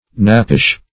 Knappish \Knap"pish\, a.
knappish.mp3